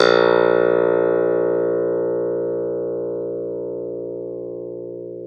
CLV_ClavDBA#2 2b.wav